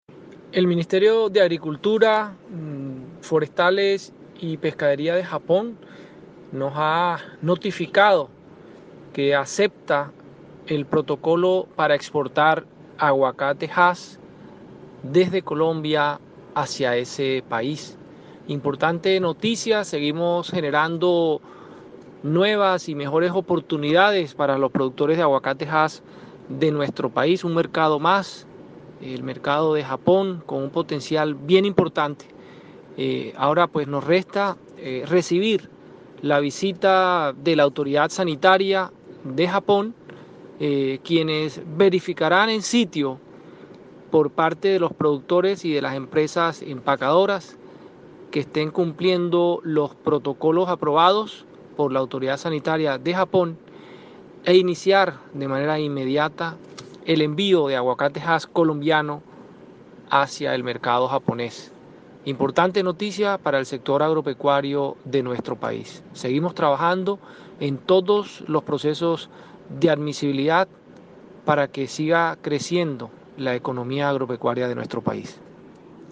Declaraciones_gerente_del_ICA
Declaraciones_gerente_del_ICA.mp3